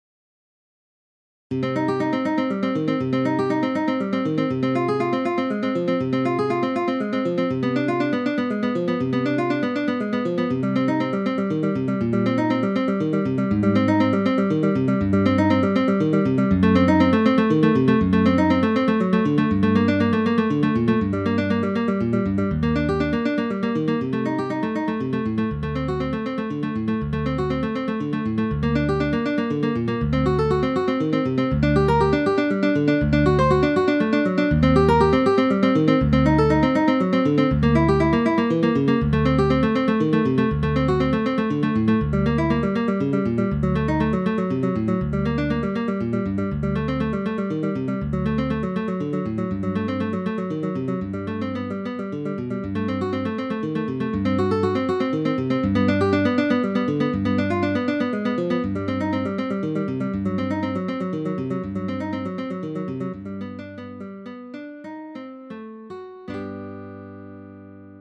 midi music.